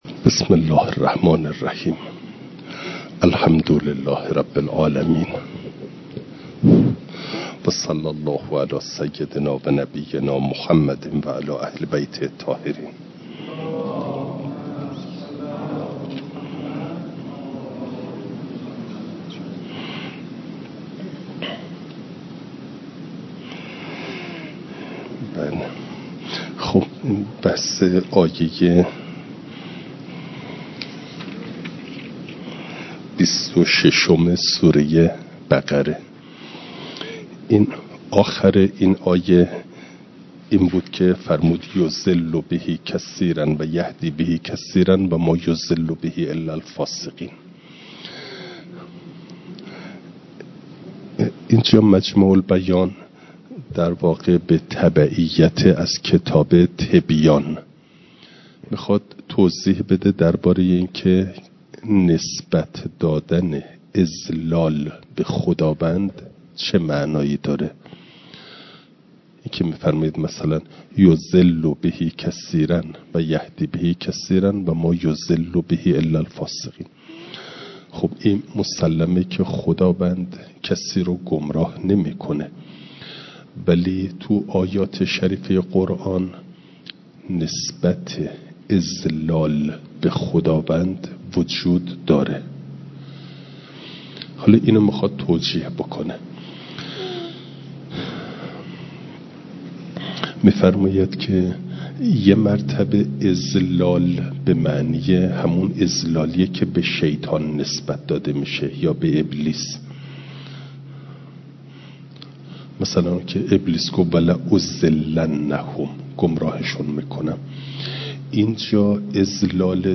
فایل صوتی جلسه سی و چهارم درس تفسیر مجمع البیان